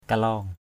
/ka-lɔ:ŋ/ 1. (d.) cây dầu lon = Dipterocarpus crispalatus. 2.